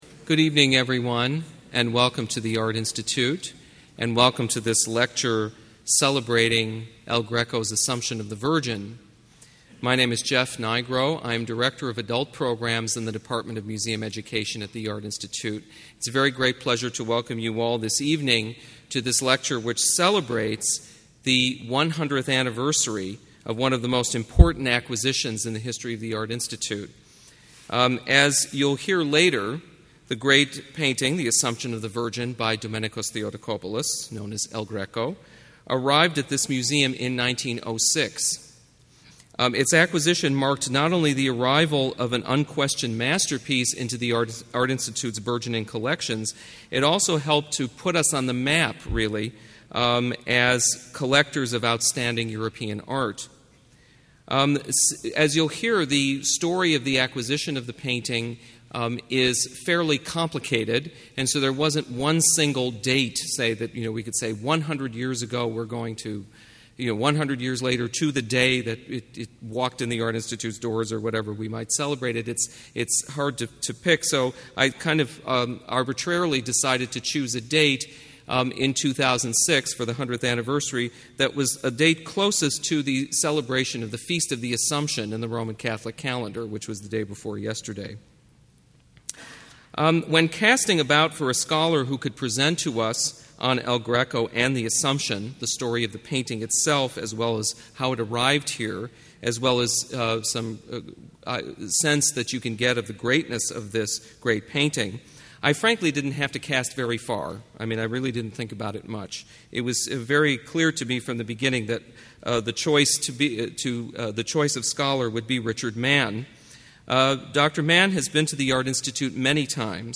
Audio Lecture